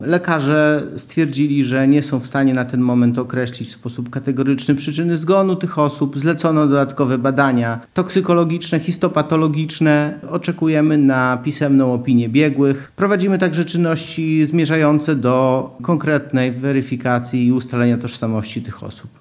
CZYTAJ: Kolejne zwłoki znalezione w Bugu [AKTUALIZACJA] – Dziś w Katedrze i Zakładzie Medycyny Sądowej Uniwersytetu Medycznego w Lublinie została przeprowadzona sekcja zwłok znalezionych ciał – mówi prokurator rejonowy Michał Roman z Prokuratury Rejonowej w Białej Podlaskiej.